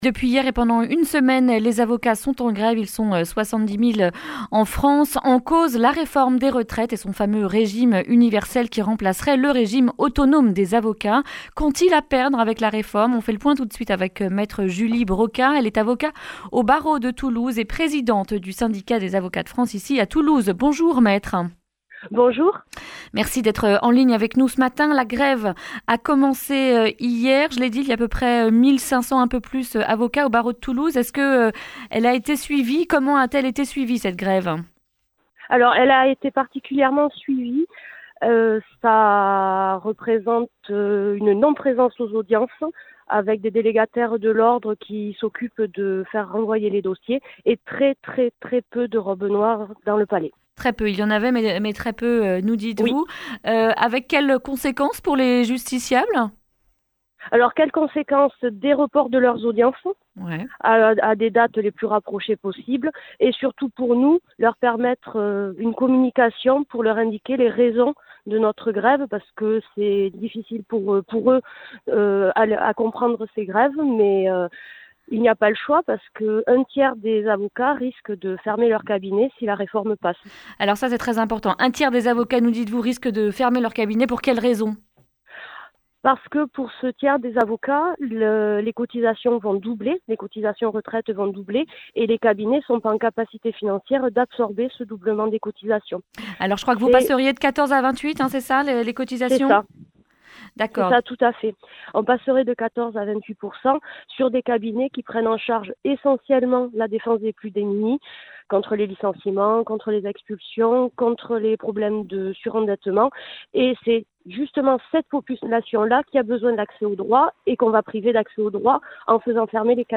mardi 7 janvier 2020 Le grand entretien Durée 11 min